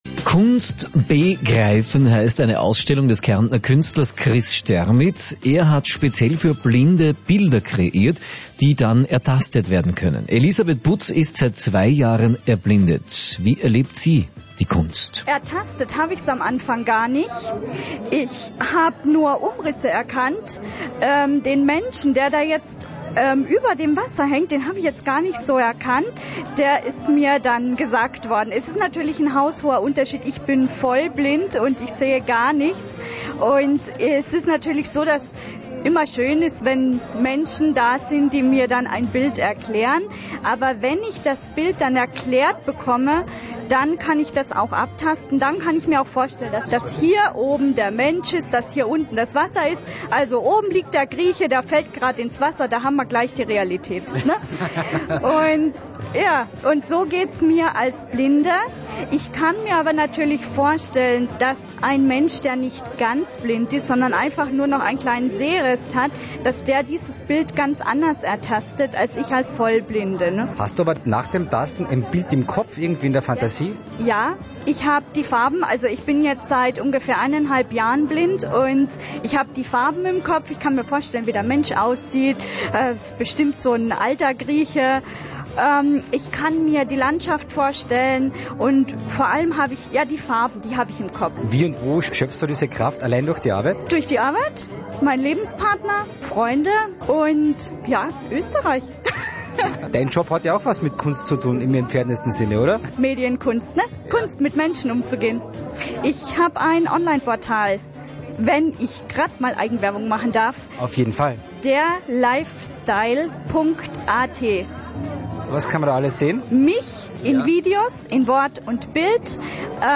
Übrigens, als ich mitten in diesem fragwürdigen Gedanken steckte, kam ein Reporter des Weges und bat mich um ein kleines Interview.